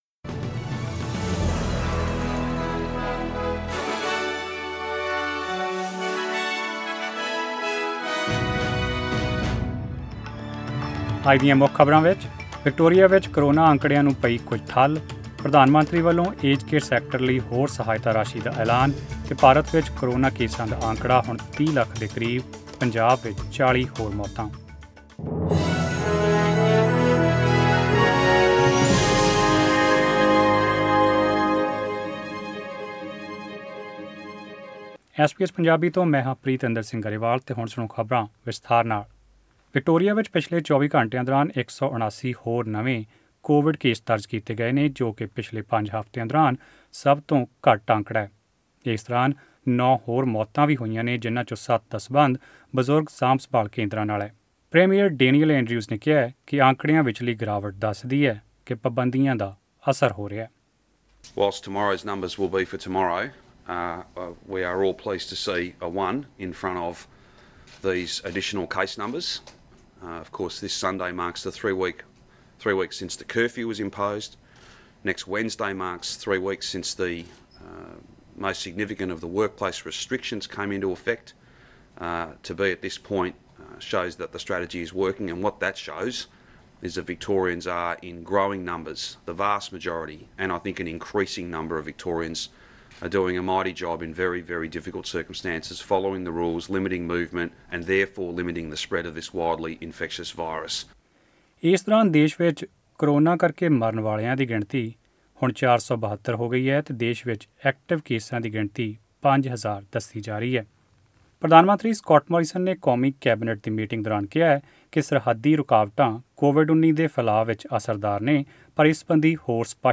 Australian News in Punjabi: 21 August 2020